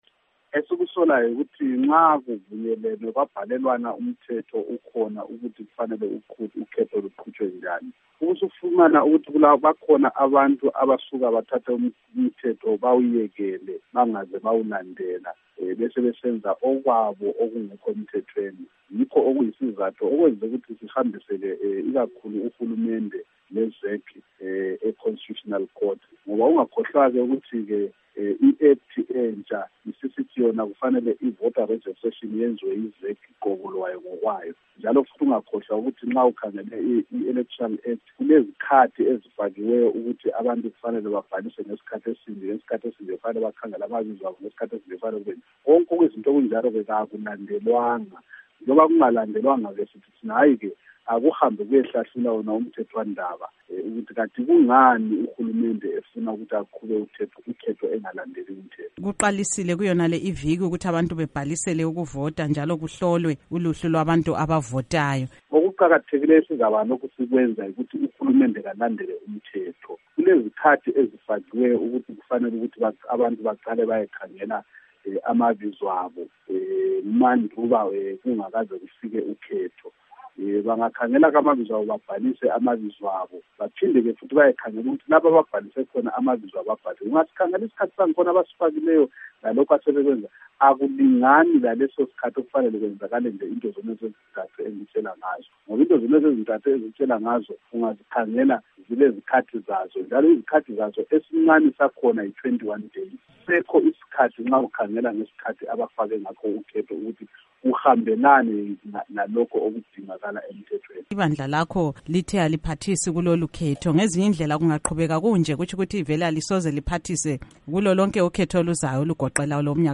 Ingxoxo loMnu. Abednigo Bhebhe